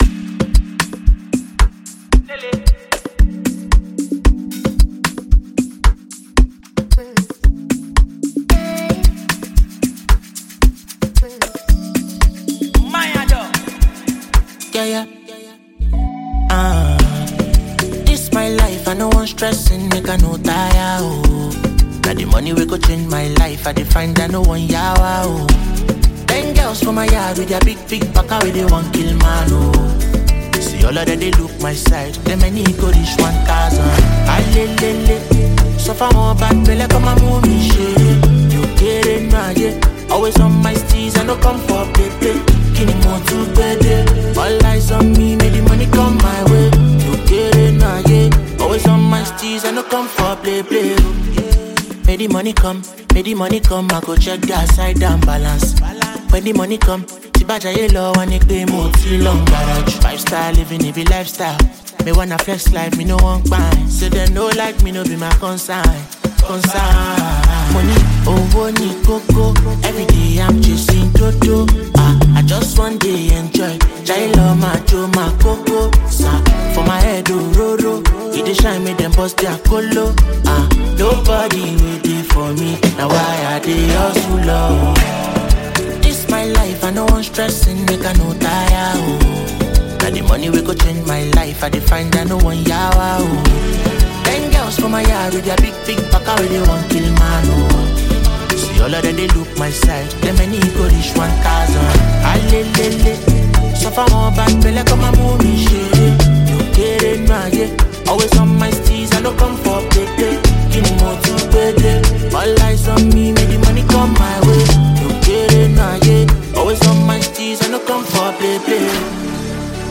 thrilling new gbedu song